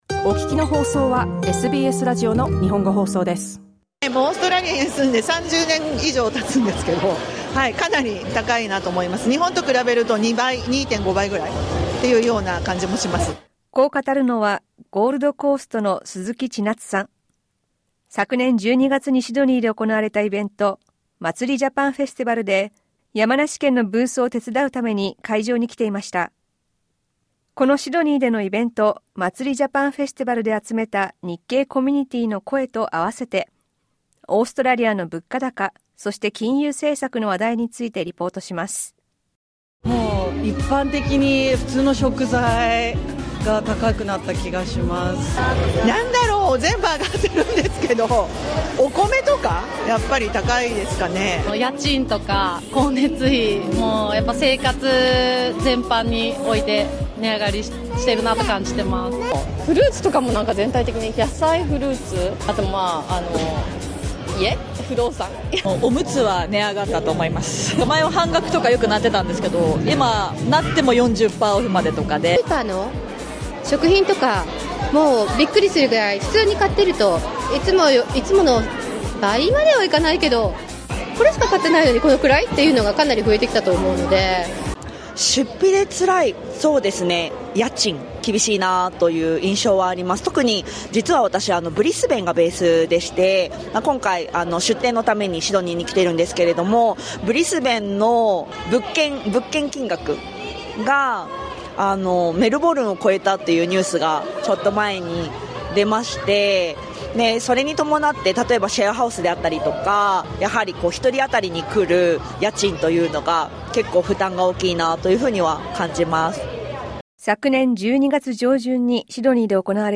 LISTEN TO 日系コミュニティーに聞く物価高、今年最初の豪政策金利決定は２月に SBS Japanese 06:49 Japanese 昨年12月７日にシドニーで行われた日本のお祭り「Matsuri Japan Festival」で集めたコミュニティーの声に、オーストラリアの経済統計とオーストラリア準備銀行（RBA）の金融政策を絡めてまとめたリポートです。